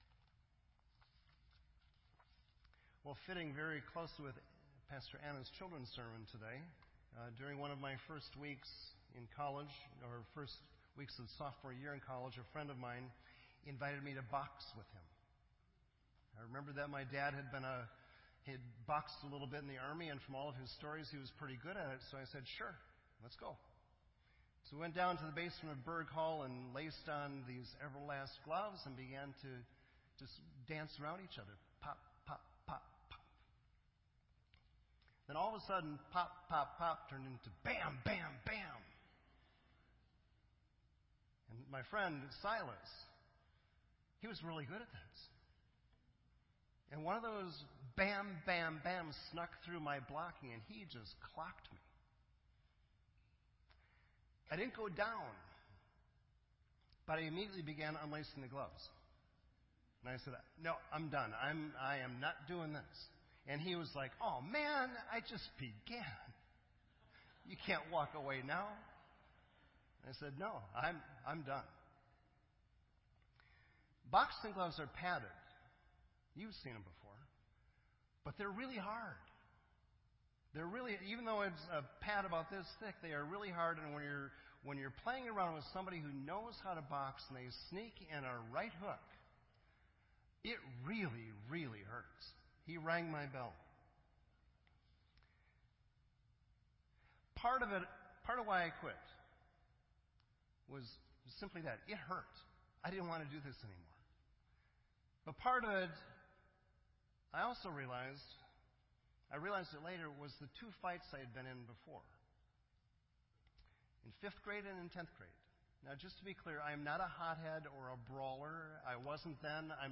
This entry was posted in Sermon Audio on August 27